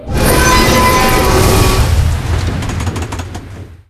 vaultScrapeNew.ogg